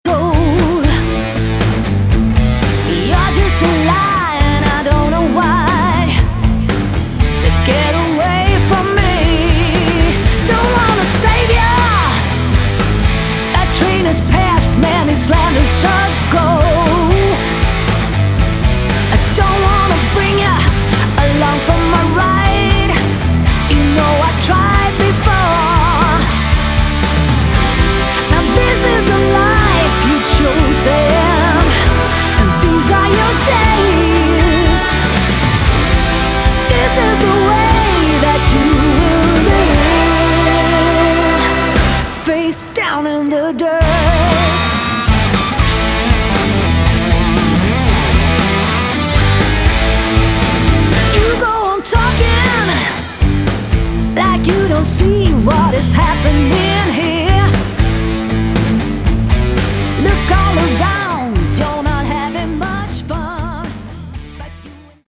Category: Hard Rock
vocals
guitars
bass guitar
drums